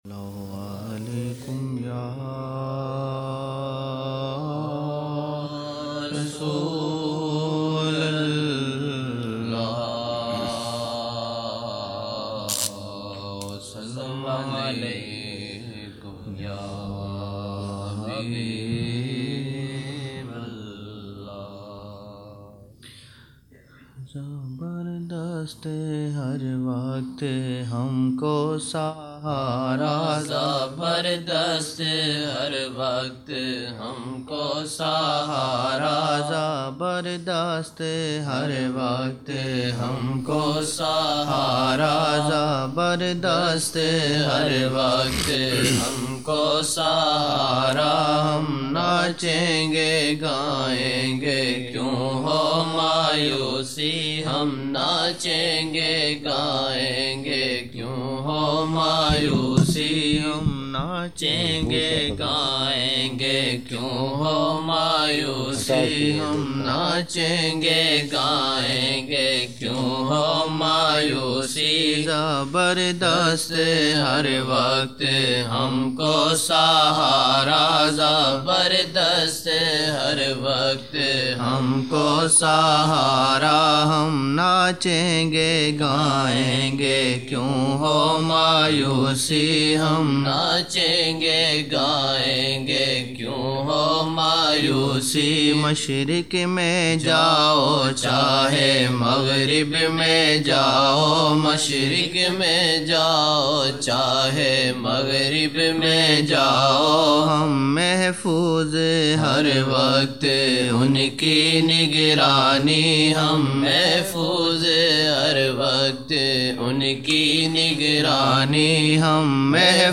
14 November 1999 - Fajr Mehfil (6 Shabaan 1420)
فجر محفل